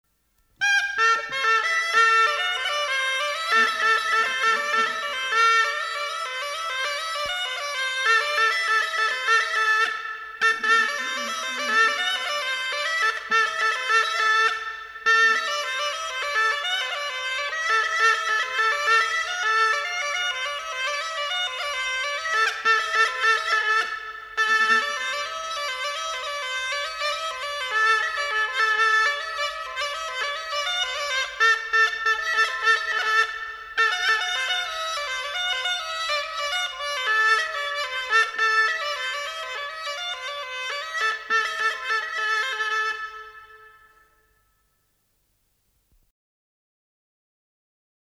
Голоса уходящего века (Курское село Илёк) Полоник (рожок, инструментальный наигрыш)